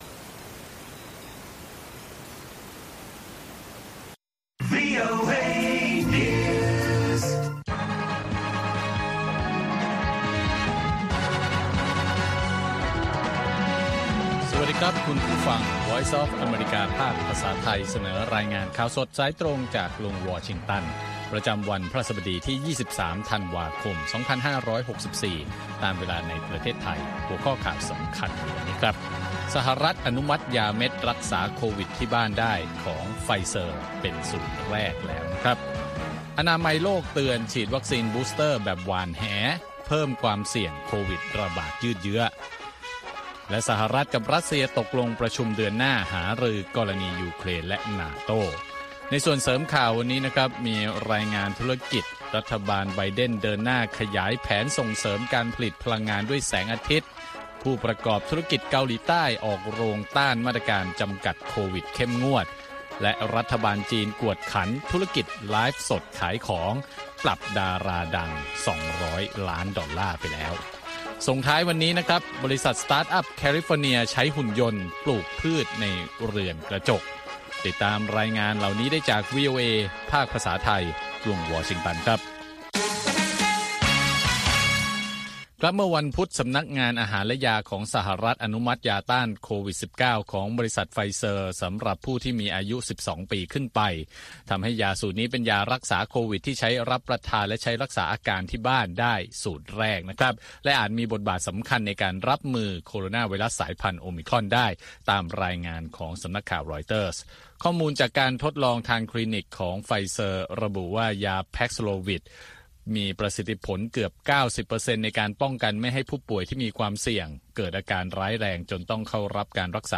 ข่าวสดสายตรงจากวีโอเอ ภาคภาษาไทย 6:30 – 7:00 น. ประจำวันพฤหัสบดีที่ 23 ธันวาคม2564 ตามเวลาในประเทศไทย